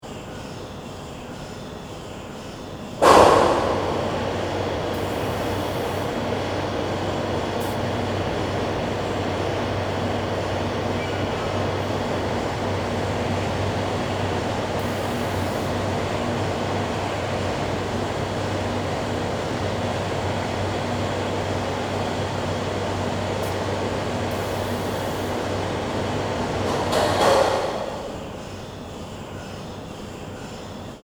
(Interior) At sunset, the 9.5 m wide observing slit of the Unit Telescopes opens to get ready for a night of observation.
ss-paranal-ut2-opening_mono.mp3